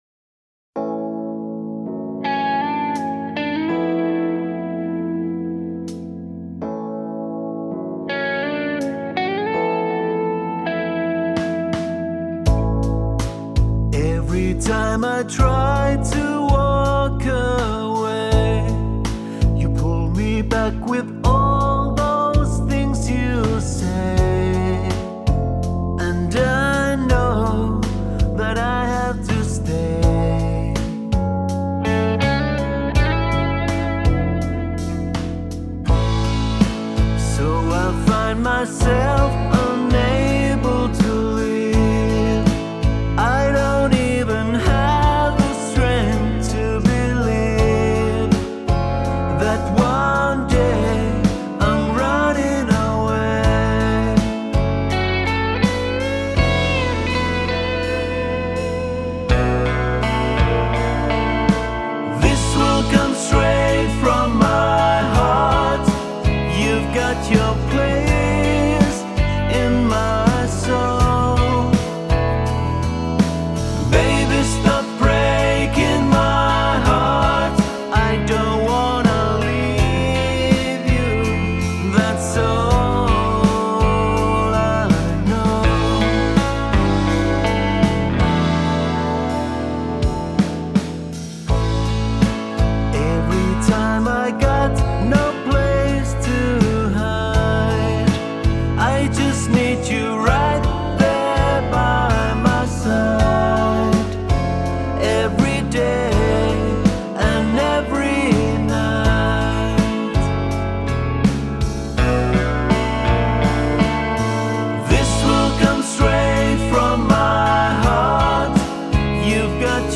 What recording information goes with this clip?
Does the vocal "effect" sounds the same in this file?